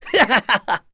Ezekiel-vox-Laugh.wav